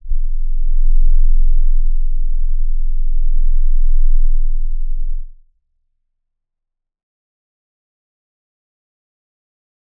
G_Crystal-E0-f.wav